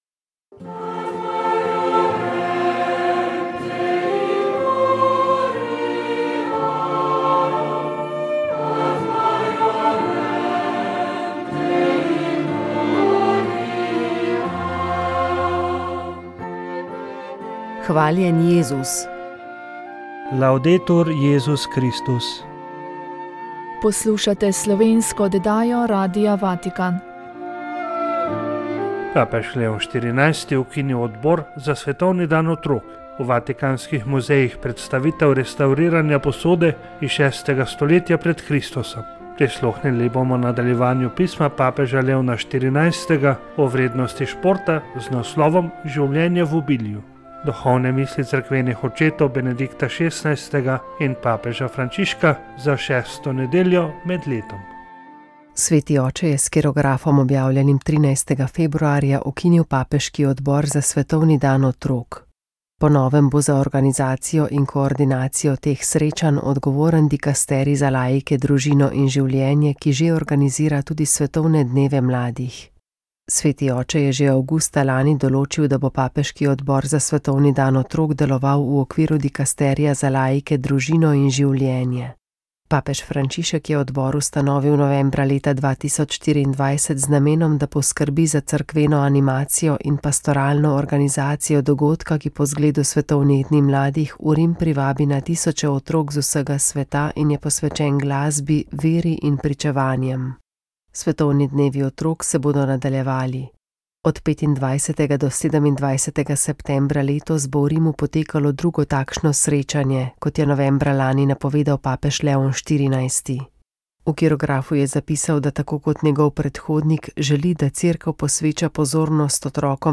Naša želja je bila odpreti prostor za iskren in spoštljiv pogovor med teisti in ateisti. Zadnja oddaja povzema ključna spoznanja, ki jih je prinesel dialog, besedo pa je imelo tudi občinstvo, ki se je zbralo ob snemanju oddaje.